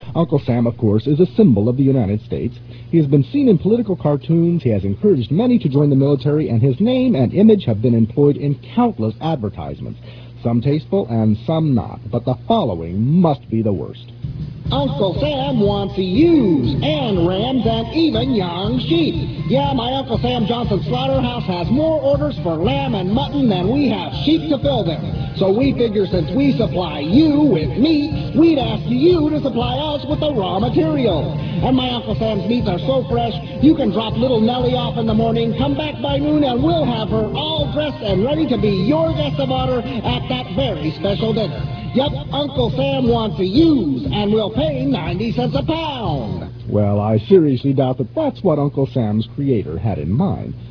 I am all voices.
The sound quality is very good considering how much it scrunches the files.
Most of those examples were captured on normal bias analog tape.